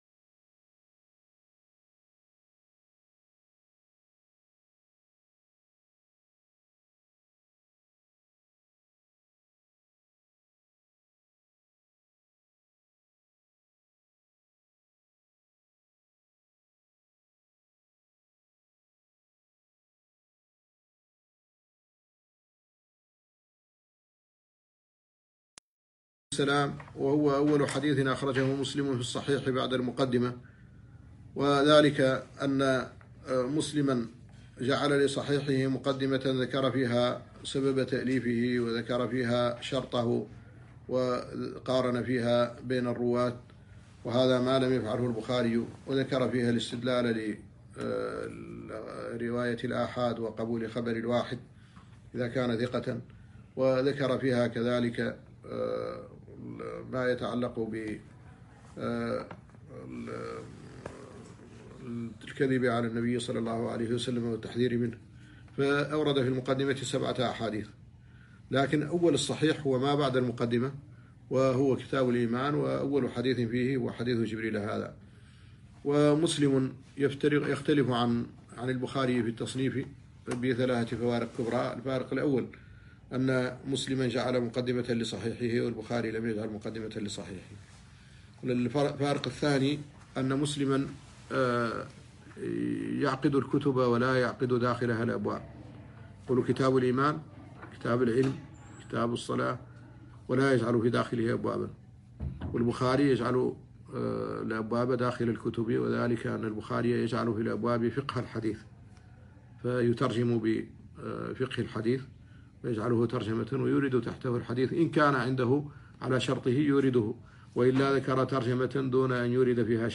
الدرس الثالث من شرح الأربعين النووية - دروس طلاب كلية الشريعة بجامعة قطر